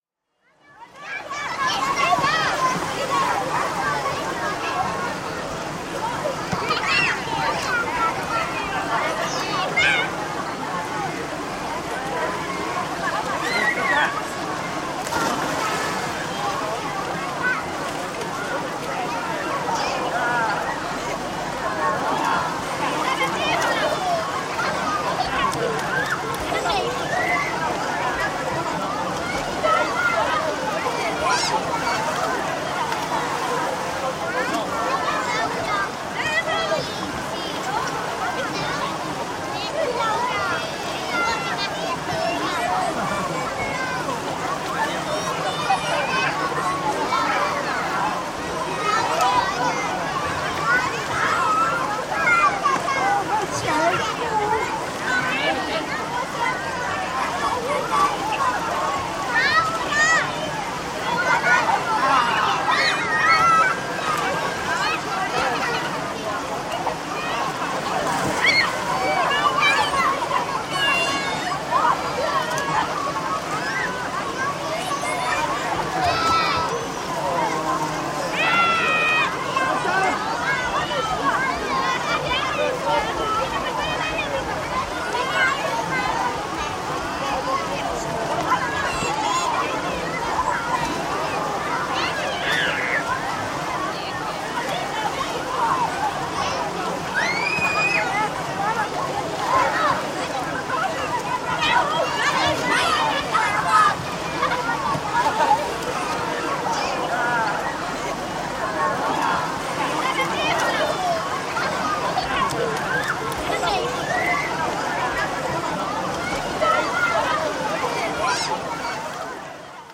Звуки аквапарка
Шум и детские крики у бассейна в аквапарке